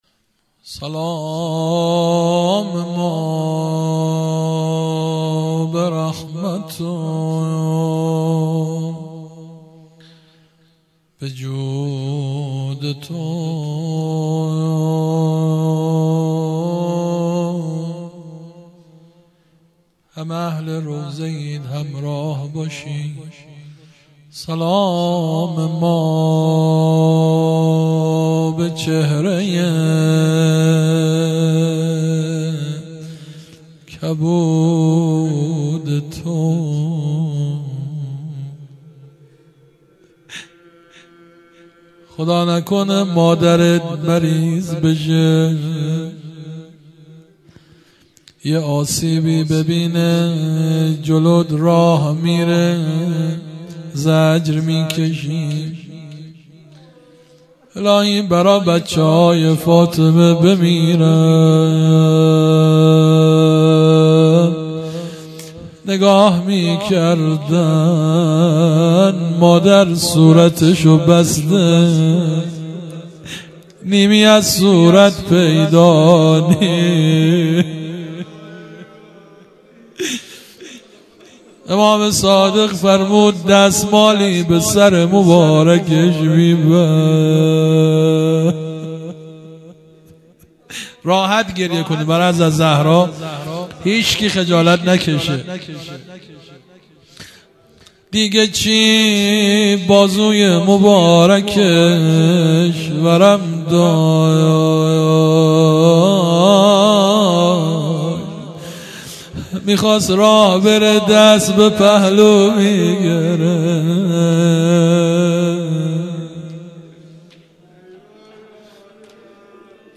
هیئت مکتب الزهرا(س)دارالعباده یزد - روضه